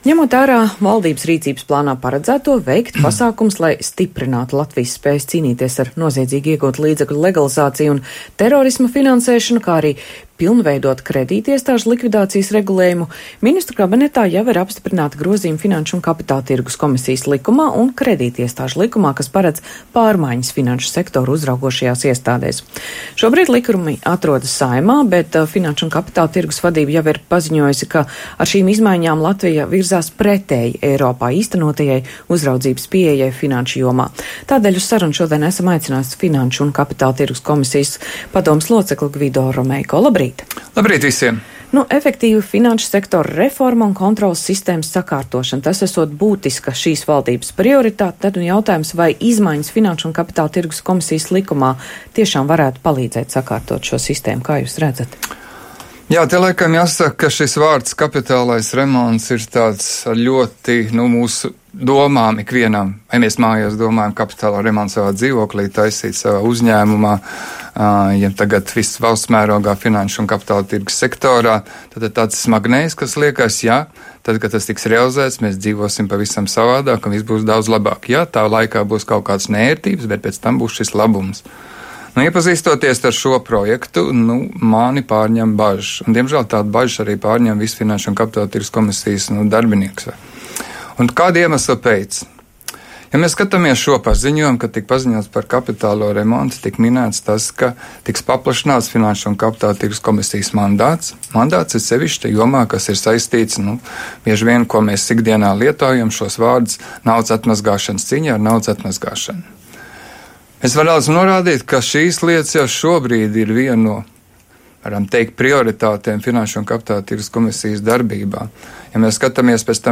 Plānotie grozījumi Finanšu un kapitāla tirgus komisijas likumā, kas paredz paplašināt komisijas funkcijas un atbildību finanšu noziegumu novēršanas uzraudzībā banku sektorā, nav nepieciešami, jo šīs funkcijas Finanšu un kapitāla tirgus komisija jau veic, un iecerētais nozares t.s. „kapitālais remonts” neko jaunu nesesīs, tā intervijā Latvijas Radio pauda Finanšu un kapitāla tirgus komisijas padomes loceklis Gvido Romeiko.